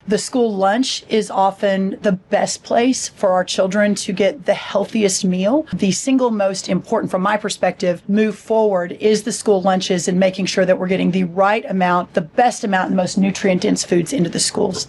Audio with Robert Kennedy, Jr., Secretary of the Department of Health and Human Services, and Ag Secretary Brooke Rollins